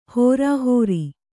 ♪ hōrāhōri